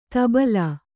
♪ tabalā